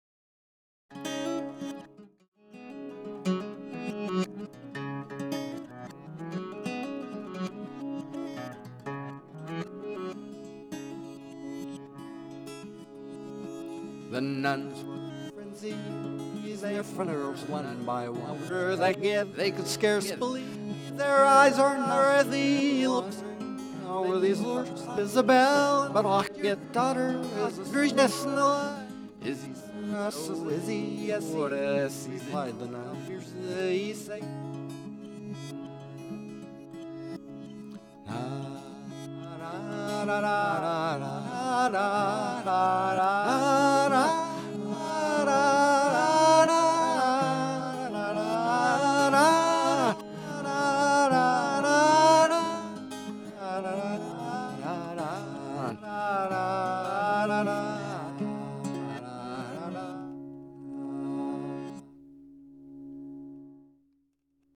"A Flask of Spanish Perfume" (This is a short excerpt from a nearly endless, and pointless, British folk ballad parody.)
Forward Backwards [mp3] — Processed through Backwards Machine in Forward Backwards mode
All examples use the default parameters (no feedback, no dry mix).